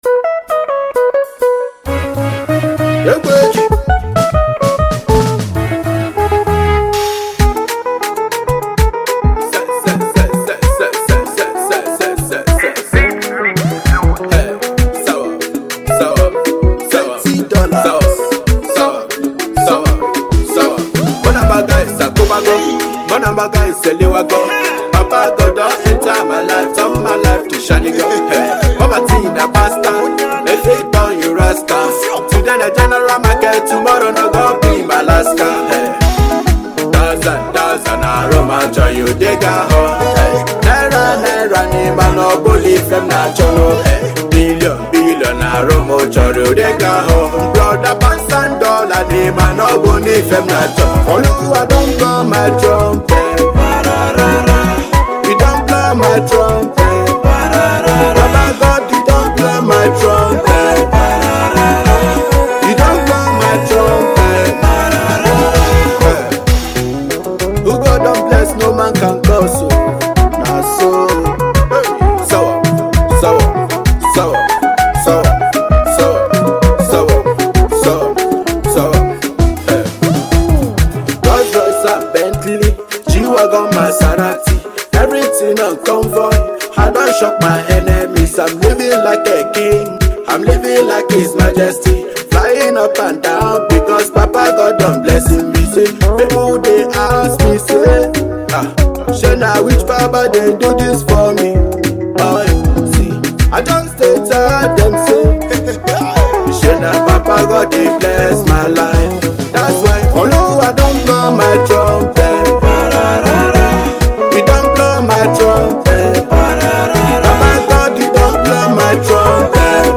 Thailand based Nigerian Rapper/Singer
motivational song